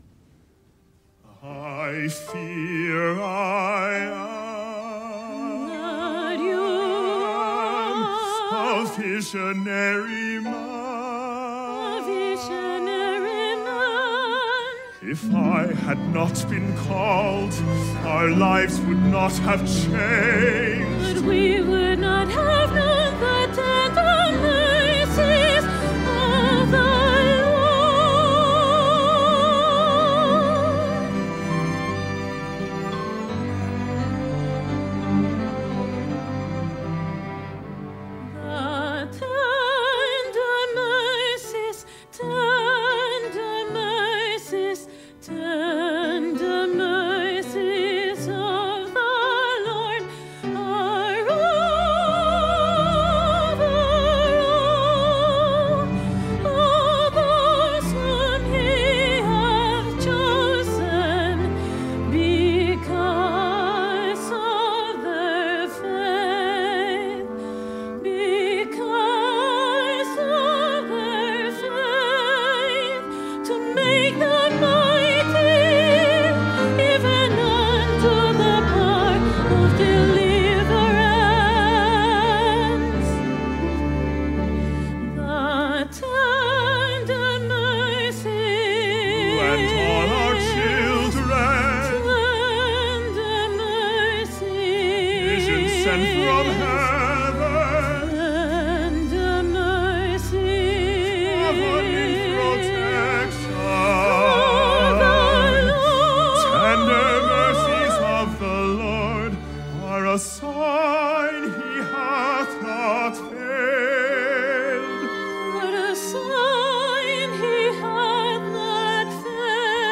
Opera Chorus
Piano OR Fl, Ob, Cl, Bn, Hn, Tr, Perc, Hp, Pno, and Str
Duet (Lehi and Sariah)